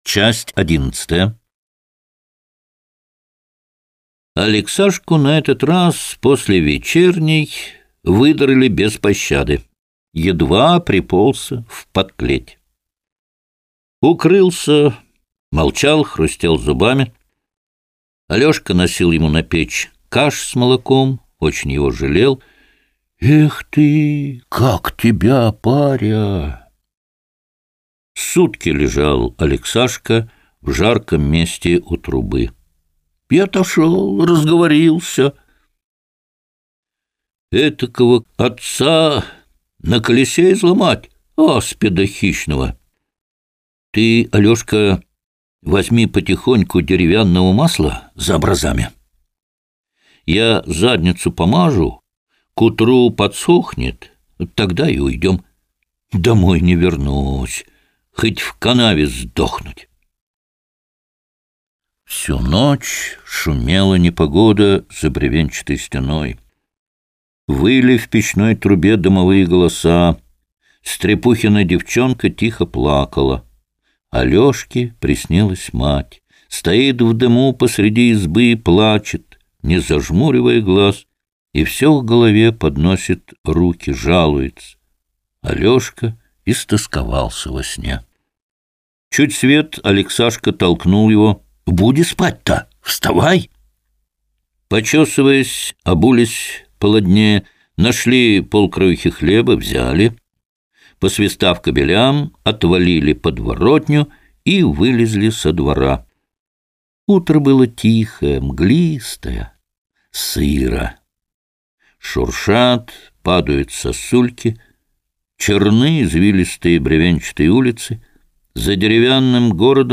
Аудиокнига Петр Первый | Библиотека аудиокниг